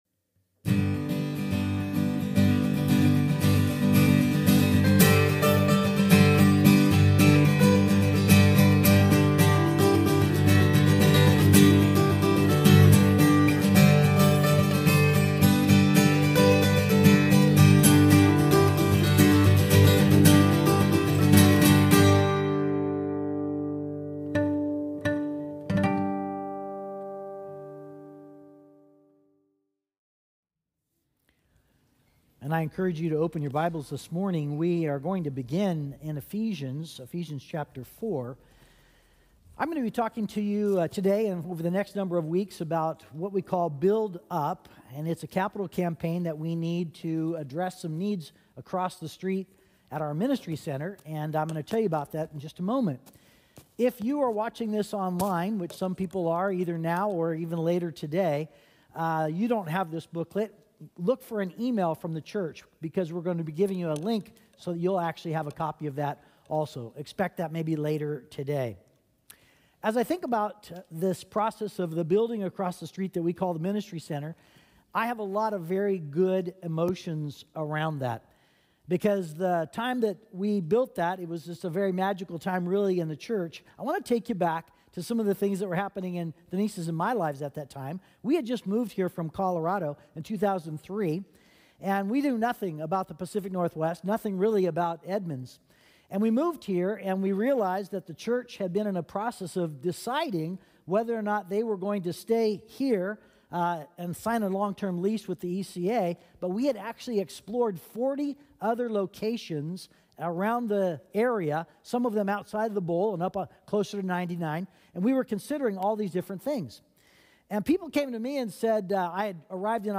Sermon Podcast from Community Christian Fellowship in Edmonds, WA.